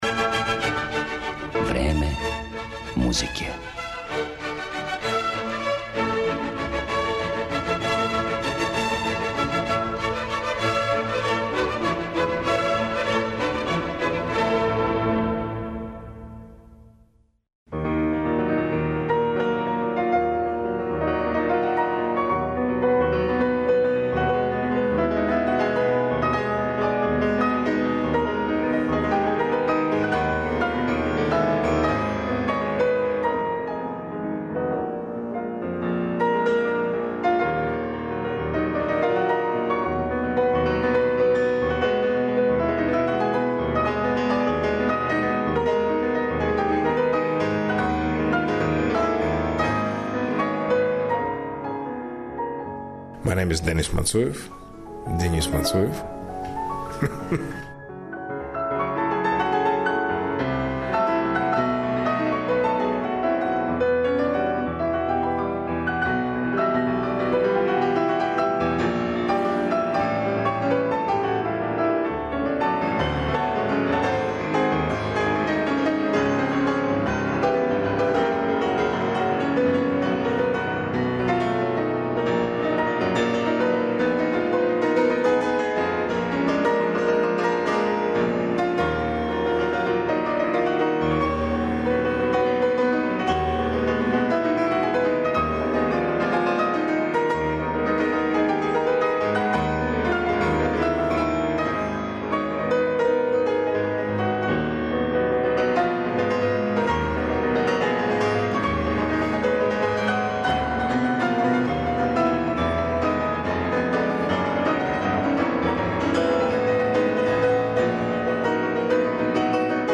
Овом изузетном солисти посвећена је данашња емисија, у којој ћете моћи да чујете интервју остварен са њим непосредно пред његов наступ у Београду, марта 2013. године.